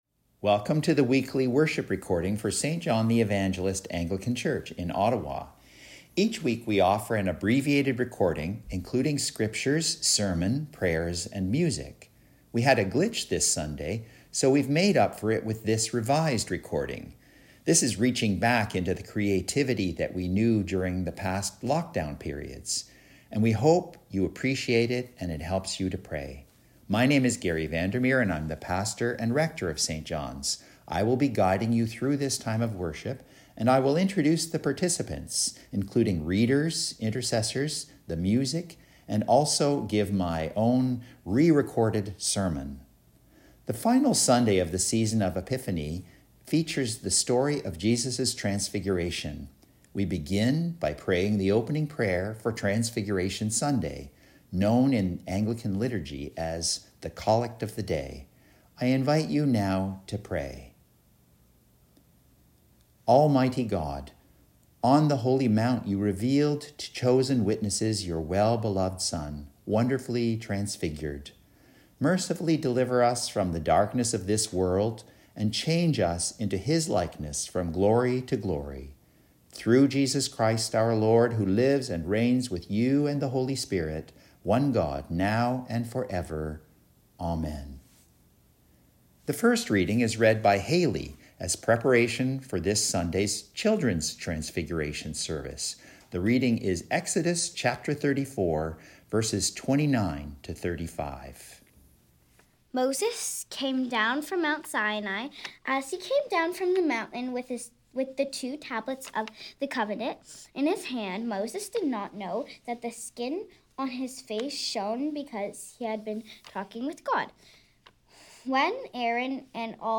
This service was re-recorded following the service due to a technical glitch.